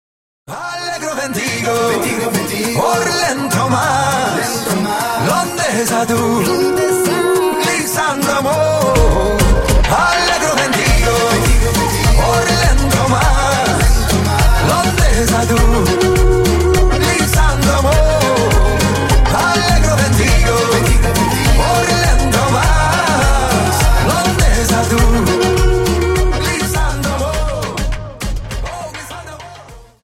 Dance: Samba 50 Song